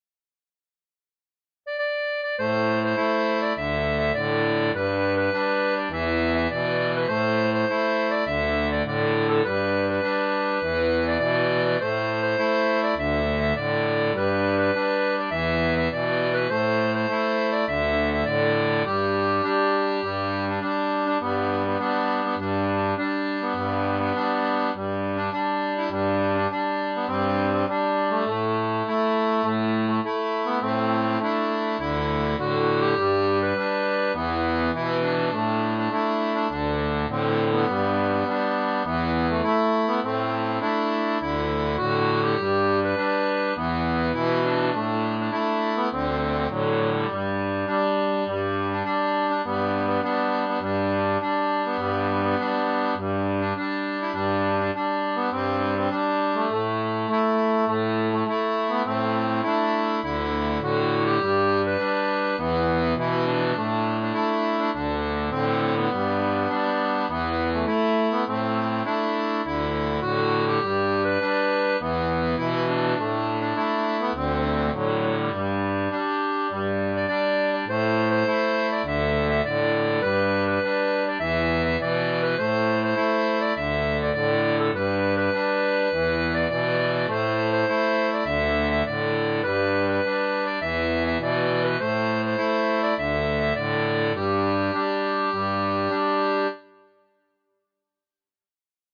• Un fichier audio basé sur la rythmique originale
Chanson française